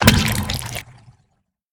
spawner-death-2.ogg